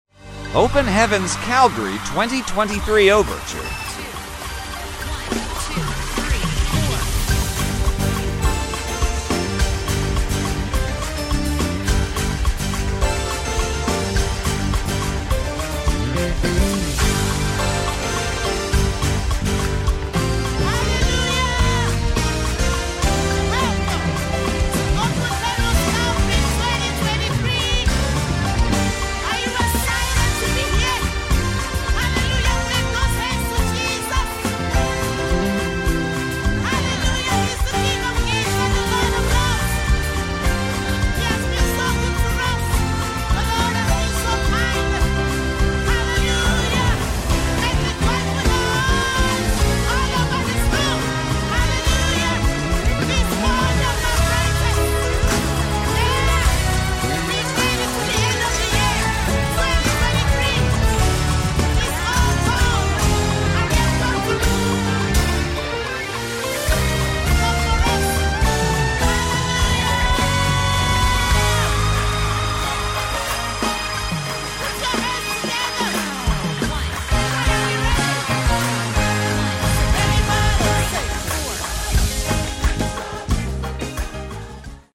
Straight fire arrangement!
(no drums, bass, main keys, & Gtrs)..
Live track
Bass
Drums
Sax